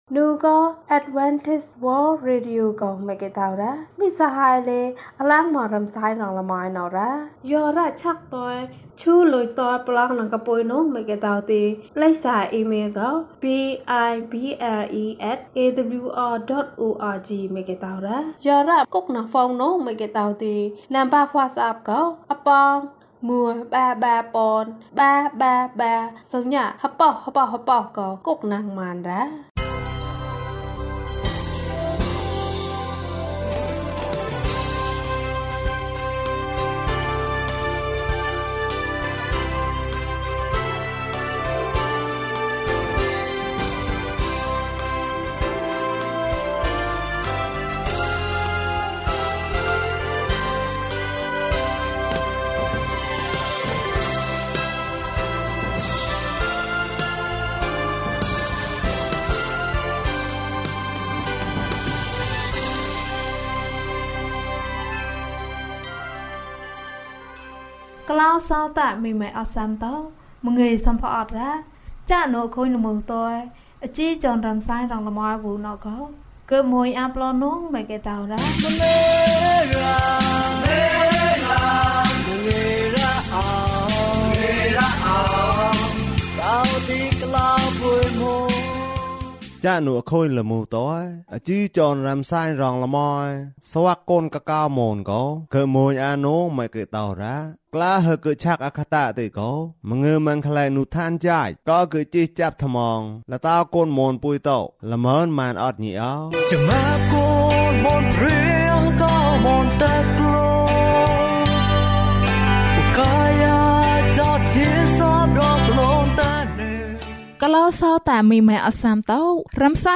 ယေရှုက သင့်ကို ခေါ်တယ်။၀၁ ကျန်းမာခြင်းအကြောင်းအရာ။ ဓမ္မသီချင်း။ တရားဒေသနာ။